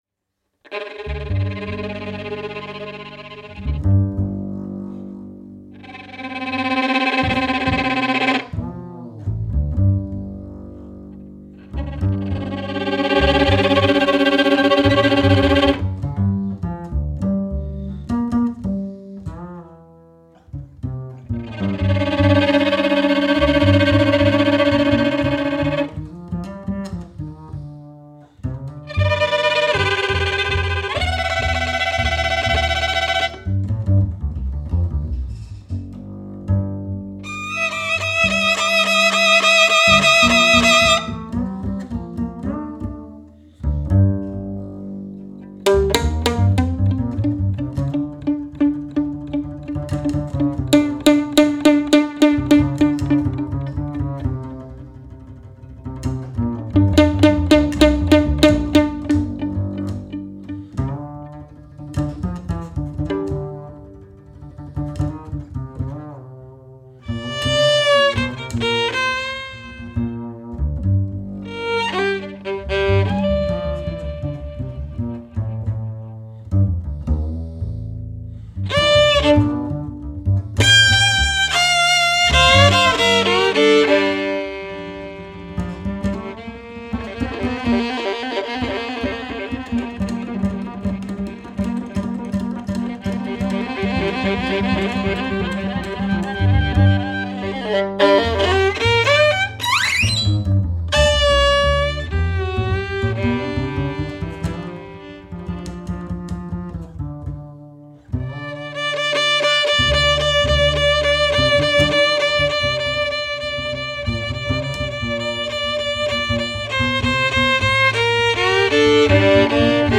violino
double bass